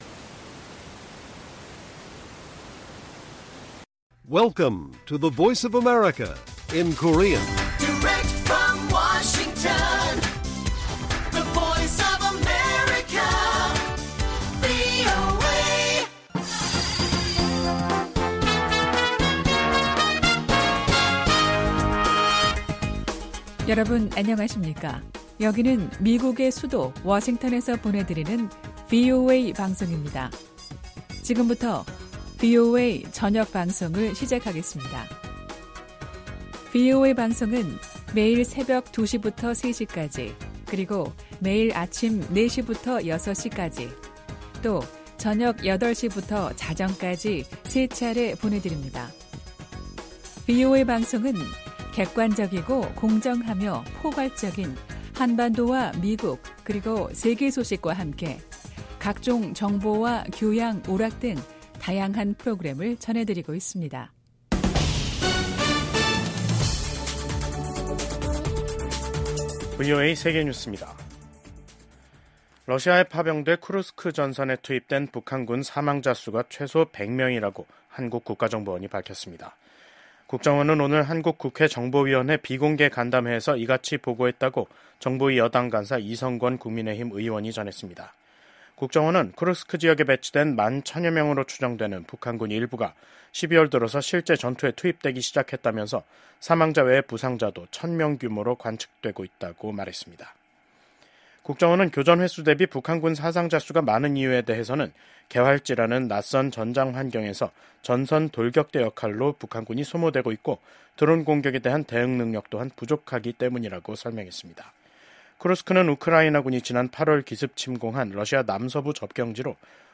VOA 한국어 간판 뉴스 프로그램 '뉴스 투데이', 2024년 12월 19일 1부 방송입니다. 한국 국가정보원은 우크라이나 전쟁에 파견된 북한 군 병사들의 전사 사실을 확인했습니다. 미국과 한국 등 유엔 안보리 이사국들이 북한의 무기 개발과 러시아에 대한 병력 파병과 무기 제공을 강하게 규탄했습니다. 미국 상원의원들은 중국이 한국의 정치적 불안정을 이용해 윤석열 대통령의 대중 정책을 바꾸고 역내 질서를 재편하려 할 가능성에 대한 우려를 표했습니다.